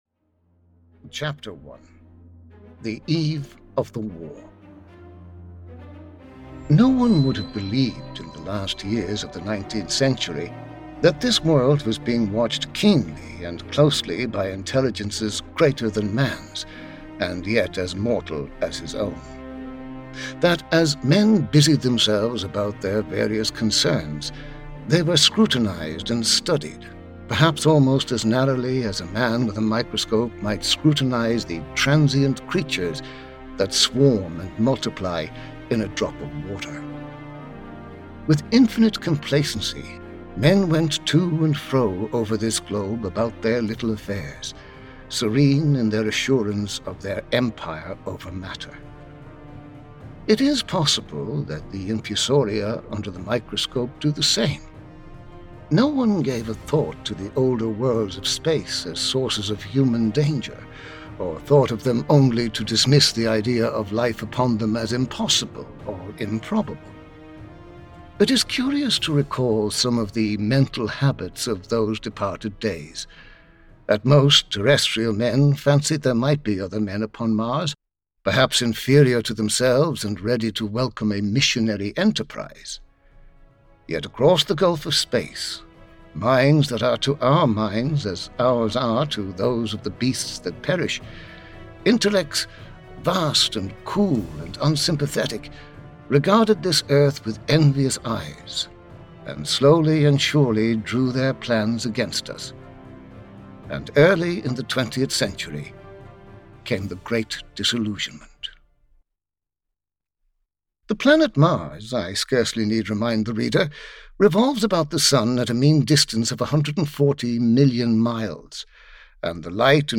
War of the Worlds (EN) audiokniha
Ukázka z knihy